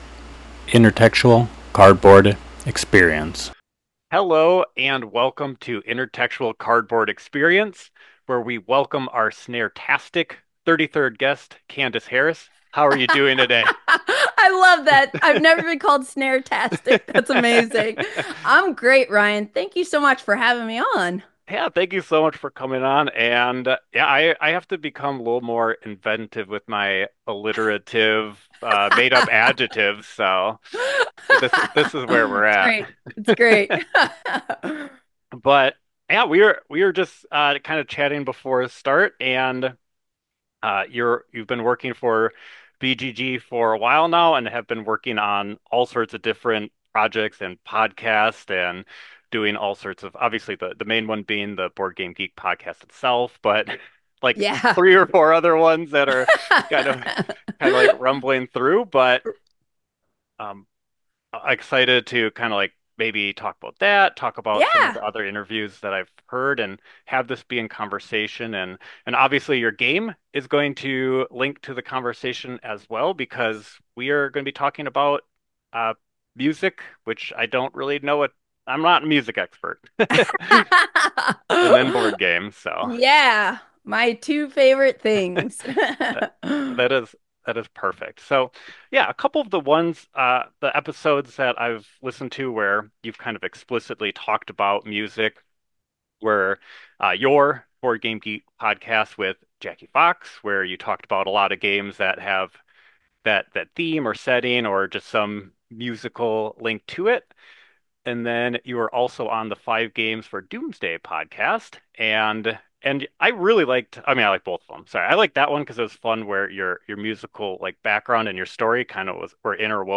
A primarily interview-based podcast grounded in the board game hobby. By exploring the connections between board games and other mediums, Intertextual Cardboard Experience is looking to tap into some links that may not have been considered much previously.